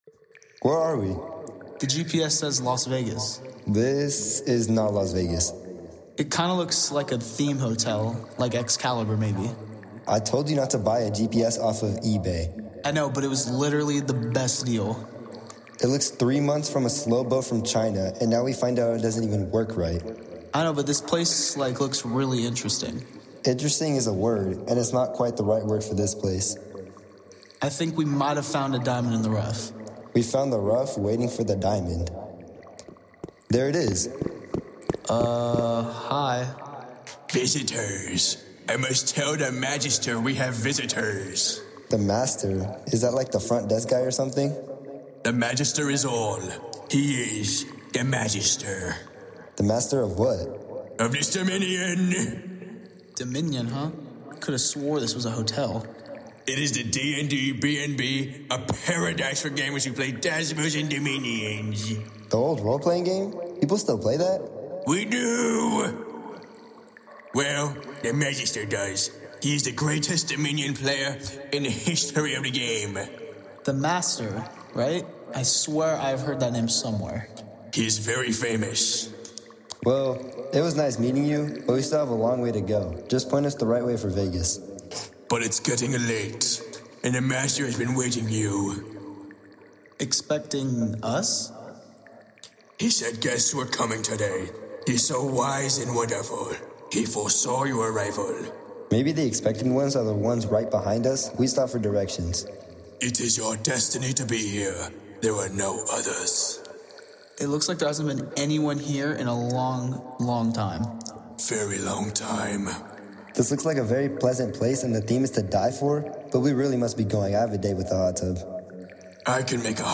Format: Audio Drama
Voices: Full cast Narrator: None Genres: Comedy, Fantasy
Soundscape: Sound effects & music